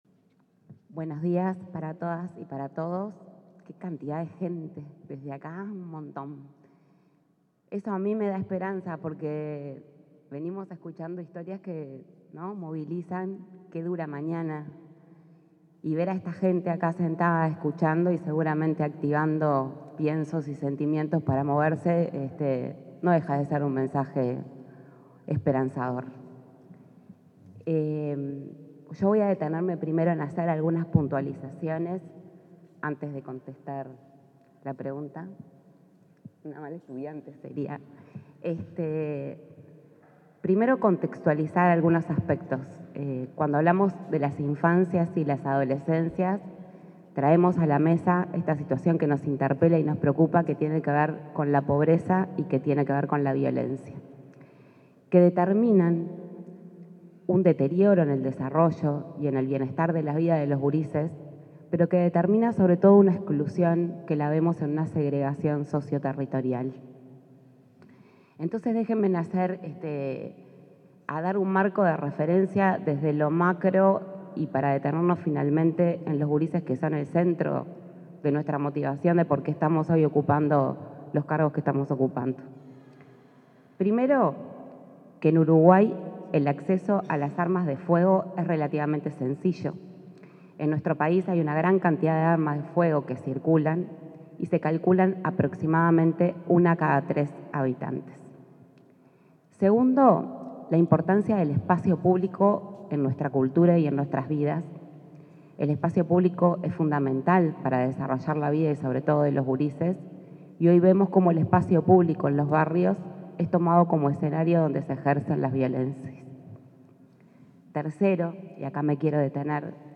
Palabras de la presidenta del INAU, Claudia Romero
En el marco del conversatorio Infancias, Adolescencias y Crimen Organizado, convocado por la organización no gubernamental Gurises Unidos, se expresó